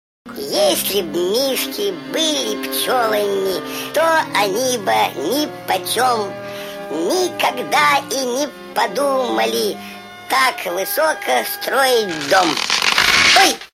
• Качество: 128, Stereo
веселые
смешные